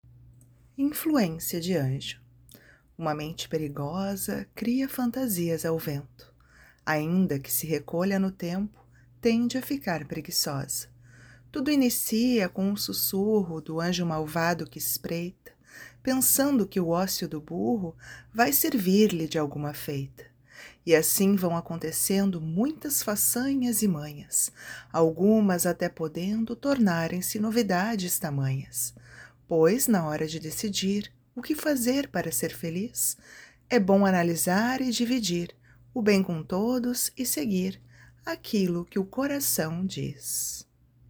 Poesias